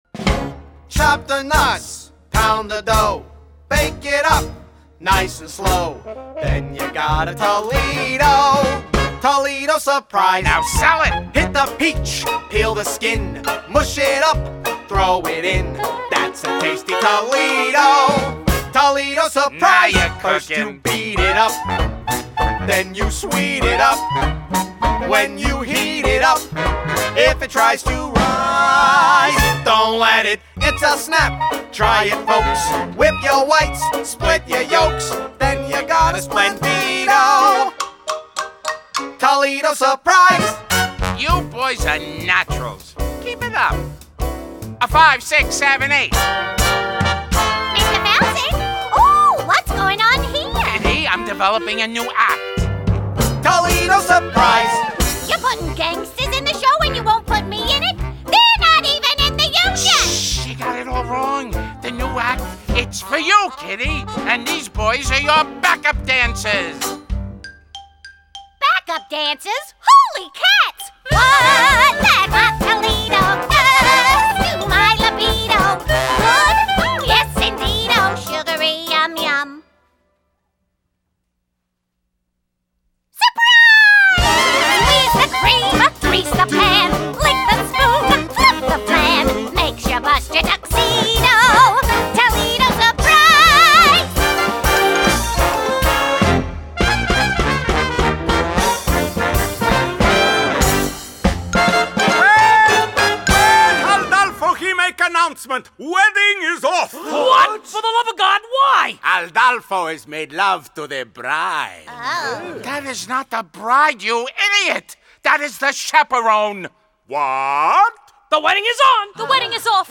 2006   Genre: Musical   Artist